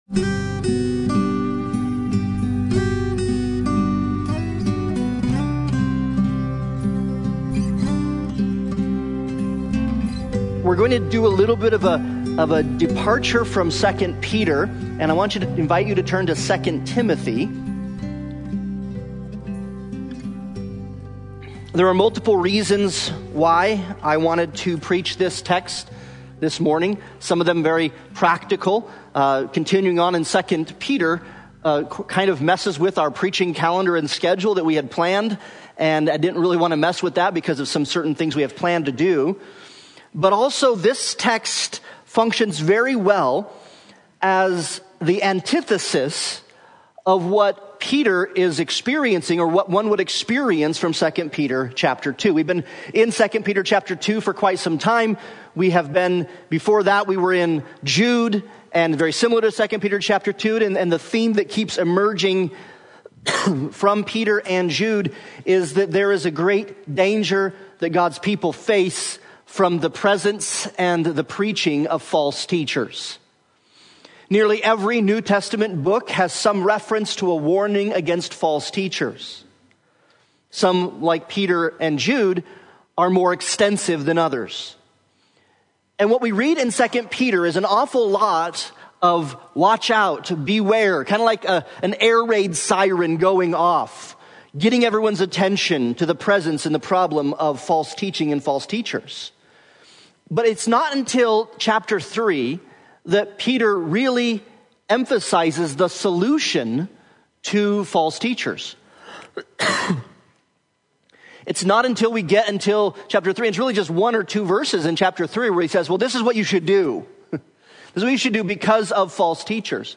2 Timothy 4:1-5 Service Type: Sunday Morning Worship « Ecclesiology